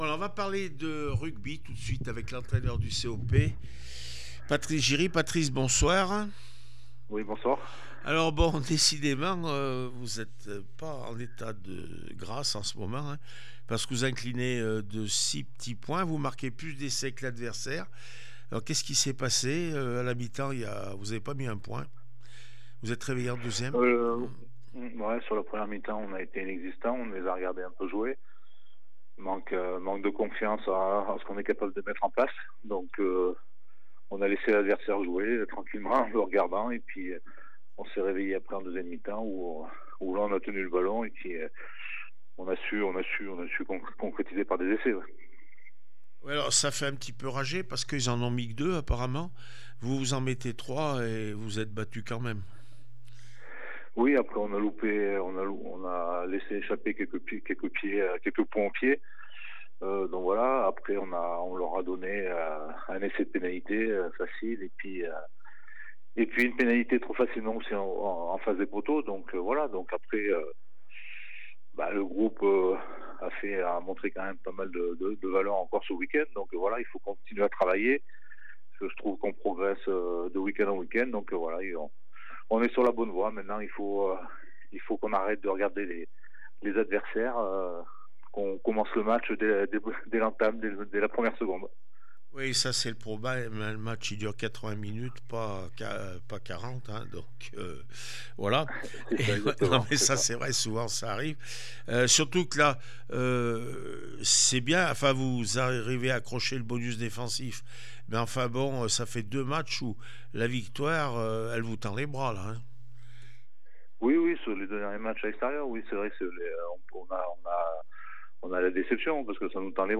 17 novembre 2025   1 - Sport, 1 - Vos interviews